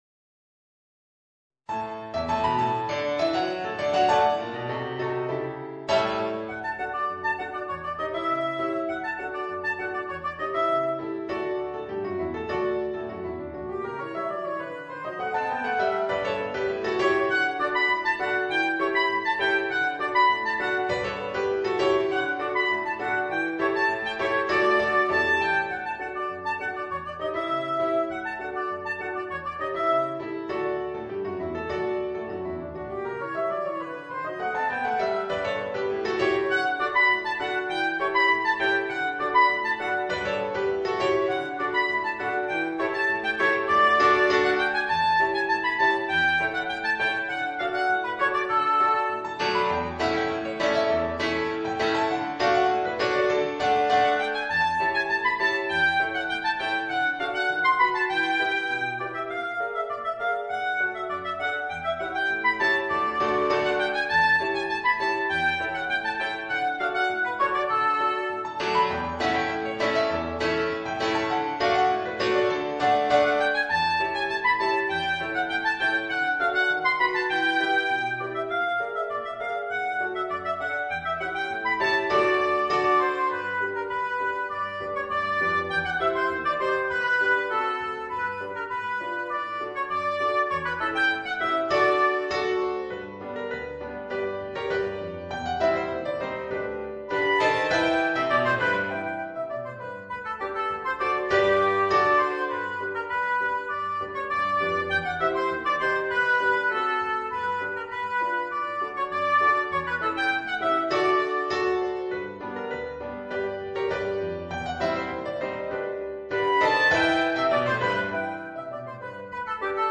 Voicing: Oboe and Piano